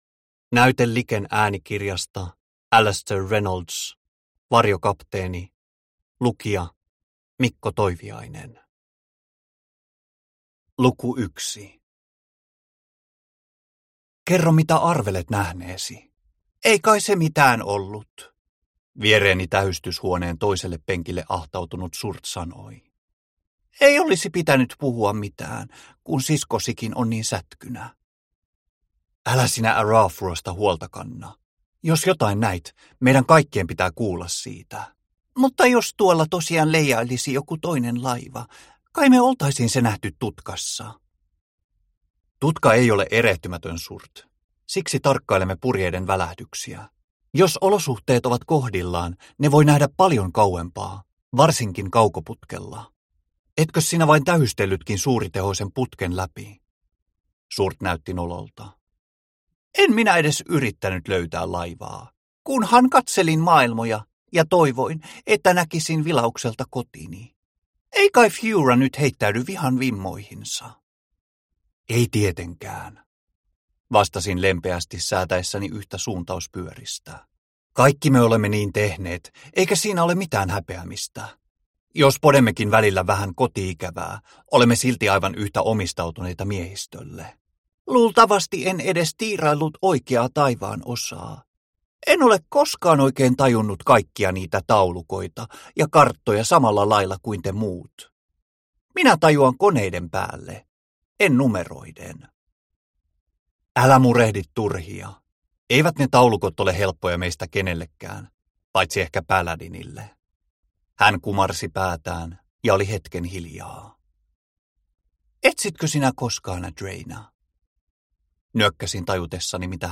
Varjokapteeni – Ljudbok – Laddas ner